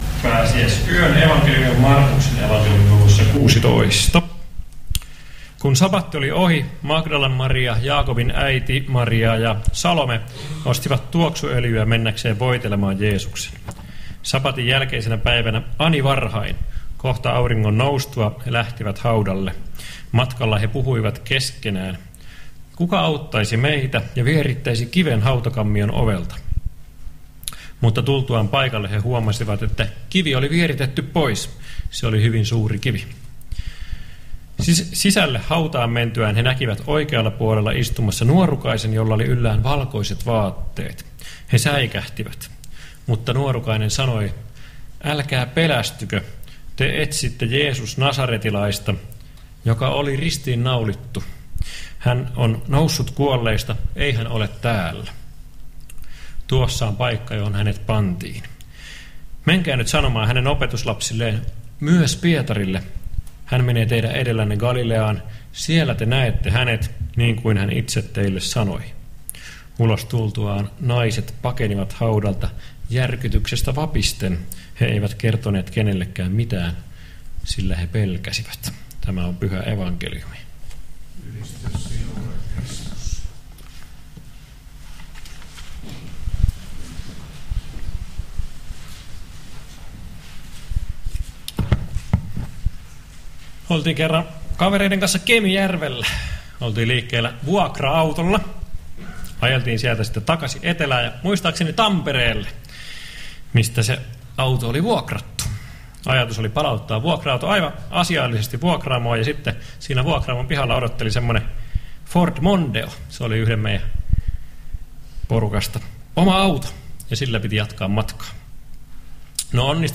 Karkussa pääsiäisyön messussa Tekstinä Mark. 16: 1-8